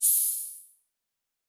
pgs/Assets/Audio/Sci-Fi Sounds/MISC/Air Hiss 3_01.wav at master
Air Hiss 3_01.wav